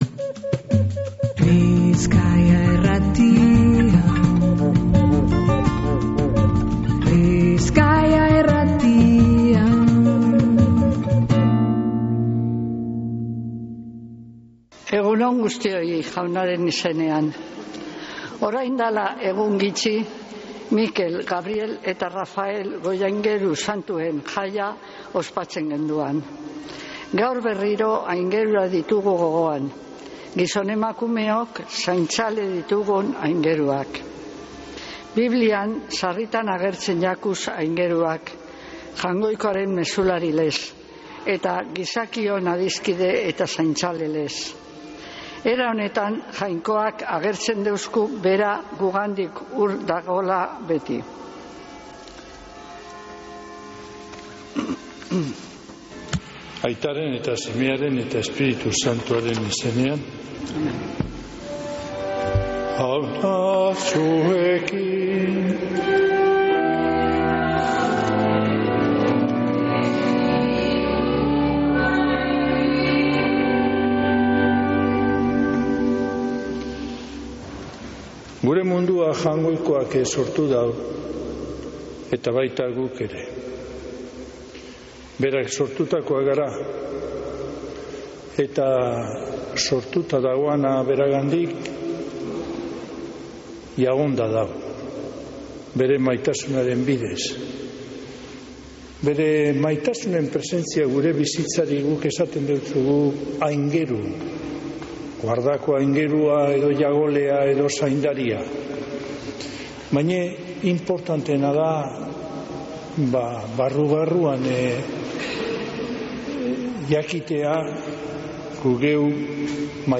Mezea